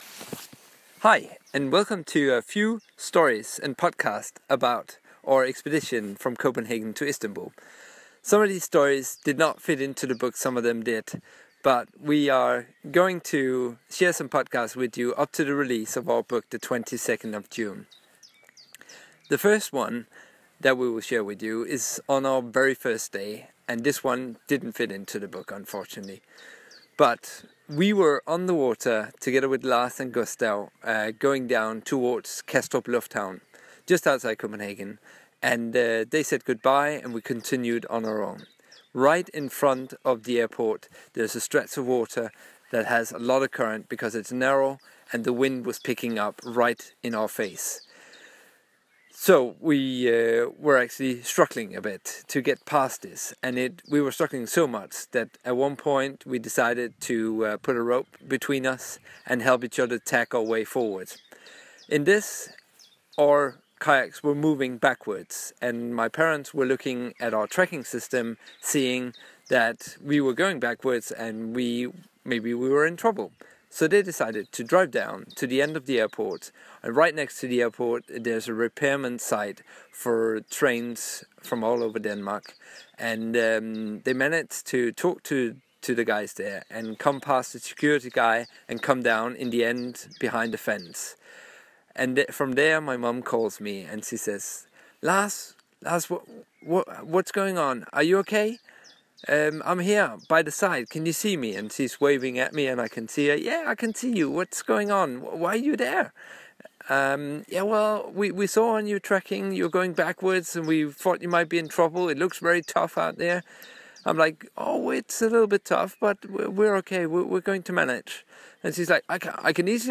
Here you can listen to a few podcast from our kayak expedition from Copenhagen to Istanbul: (It is being updated at the moment may/june 2016) Some storys didn't make it to THE BOOK some did, enjoy!!